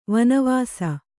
♪ vana vāsa